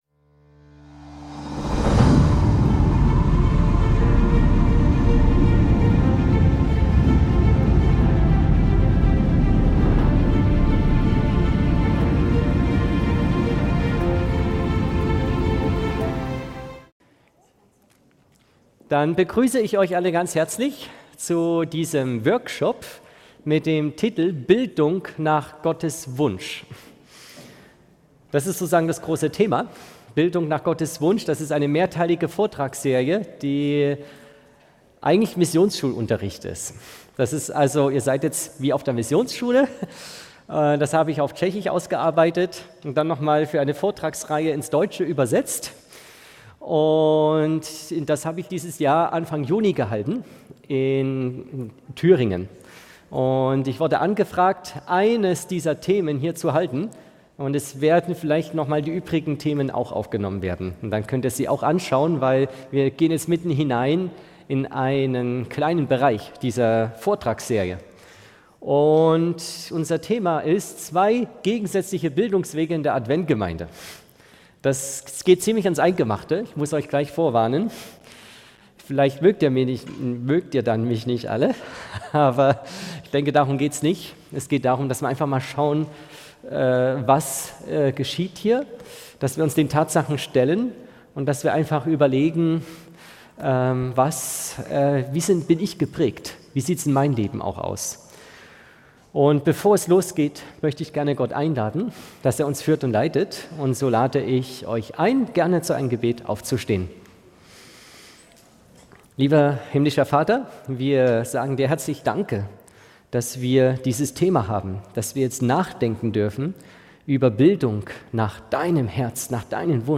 In einem bewegenden Workshop über Bildung nach Gottes Wunsch werden zwei gegensätzliche Bildungswege innerhalb der Adventgemeinde beleuchtet. Historische und aktuelle Aspekte der adventistischen Bildung werden miteinander verknüpft, die Bedeutung der missionarischen Erziehung hervorgehoben und die Herausforderungen der Akkreditierung thematisiert.